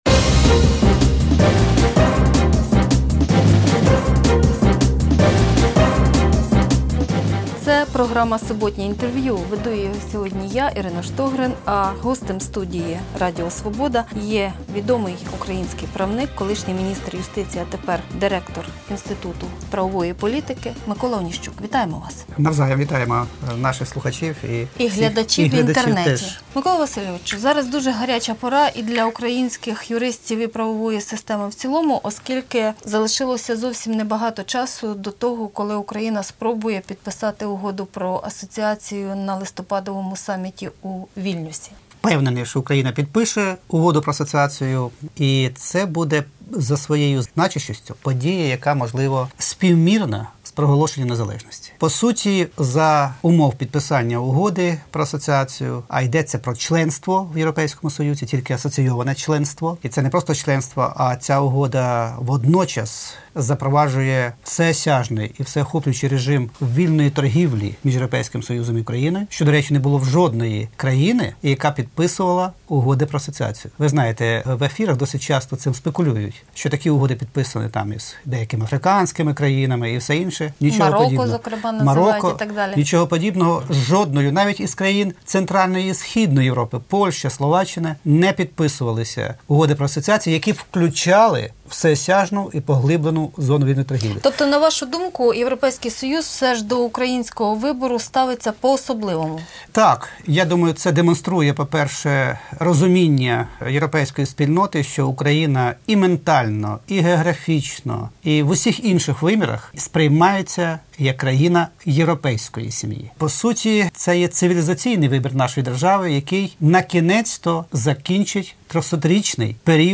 Суботнє інтерв'ю